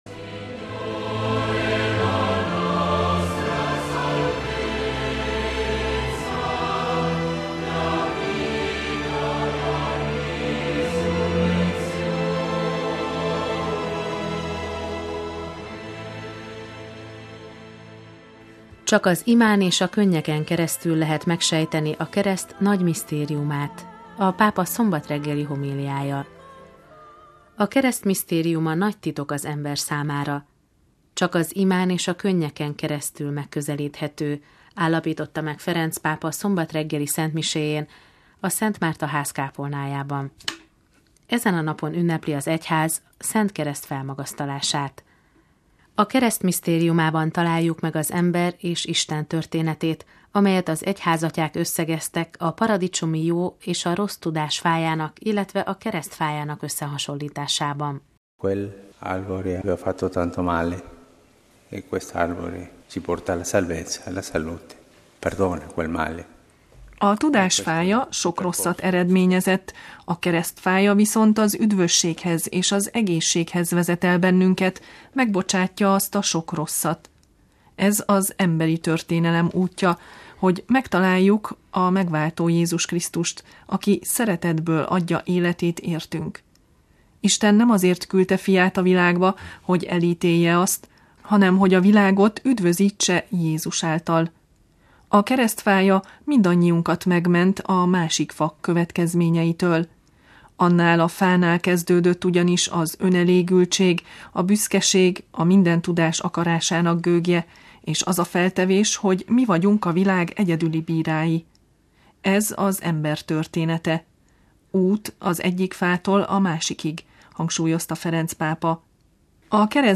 Csak az imán és a könnyeken keresztül lehet megsejteni a Kereszt nagy misztériumát – a pápa szombat reggeli homíliája
MP3 A Kereszt misztériuma nagy titok az ember számára. Csak az imán és a könnyeken keresztül megközelíthető – állapította meg Ferenc pápa szombat reggeli szentmiséjén a Szent Márta Ház kápolnájában.